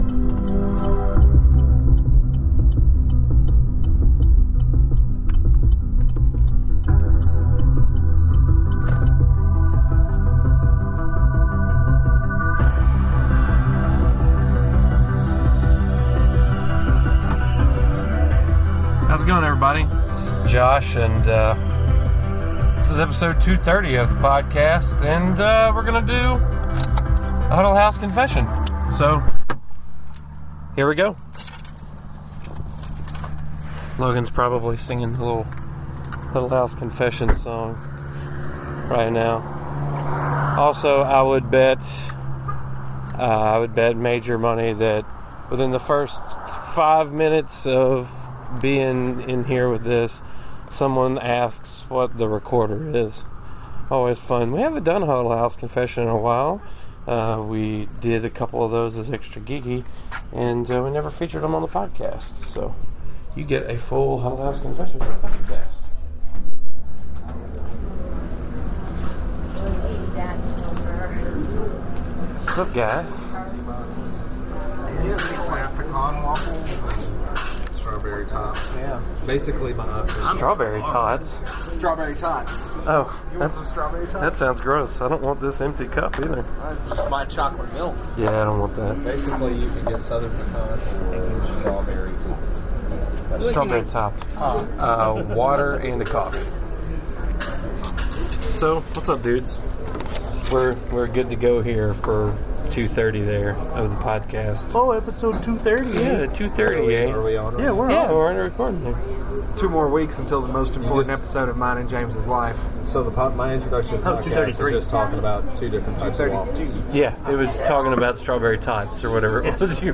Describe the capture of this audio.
Get a different taste of the Geeks in this week’s episode, recorded on location at the nearest Huddle House.